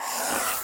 铅笔划线.wav